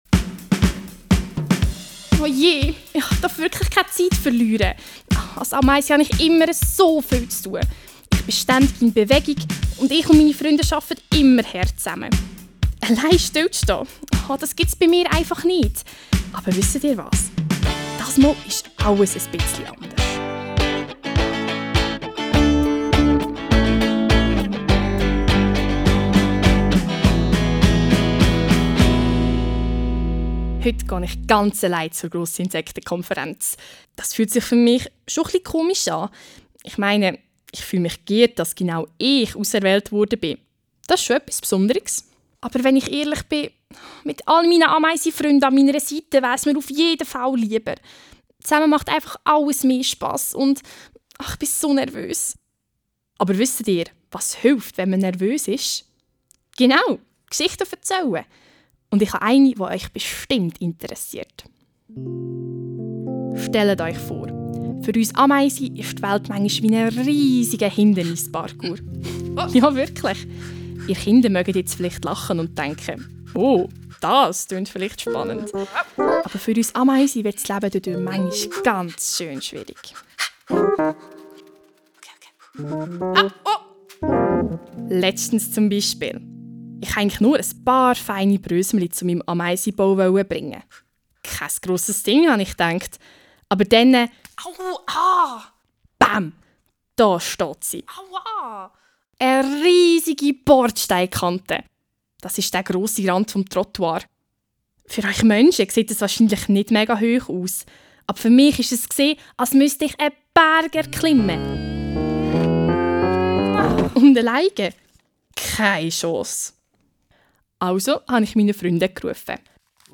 LS Posten Erde Der Posten startet mit einer Geschichte der Ameise Hektora. Ihr erfahrt mehr über die Herausforderungen einer kleinen Ameise in unserer heutigen Umwelt. Startet mit dem Hörspiel: Hört das Hörspiel über eure Lautsprecher.